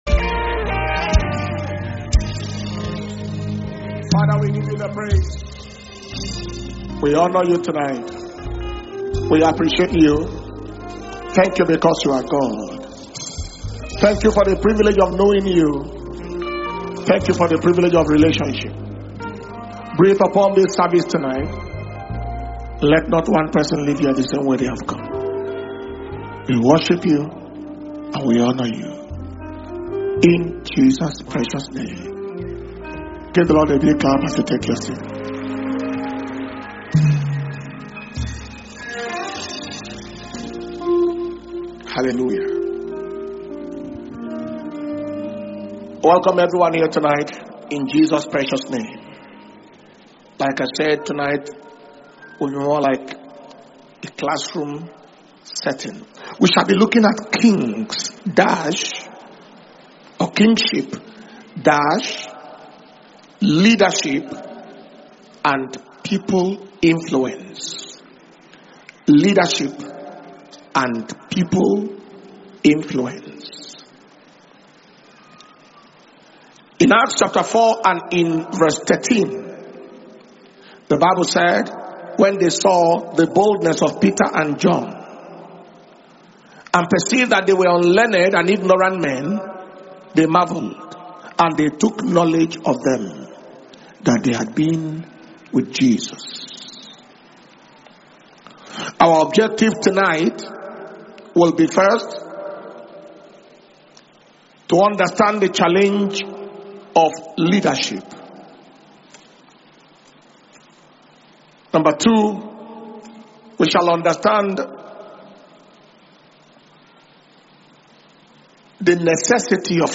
Dunamis Kingdom Power And Glory World Conference 2025 – KPGWC2025